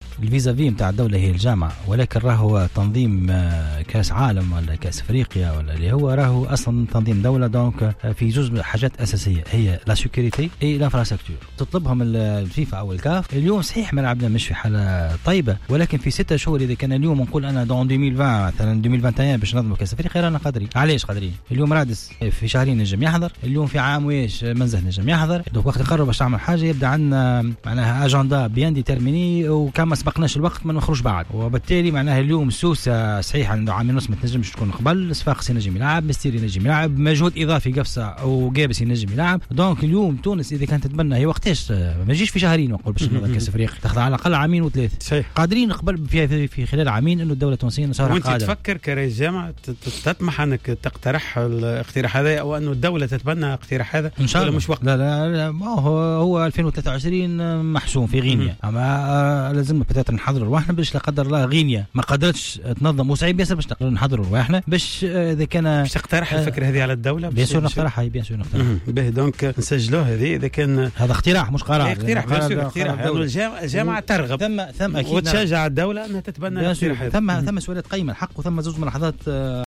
أكد رئيس الجامعة التونسية لكرة القدم وديع الجريء ضيف حصة "قوول" أن تونس قادرة على تنظيم نهائيات كأس إفريقيا في السنوات القادمة.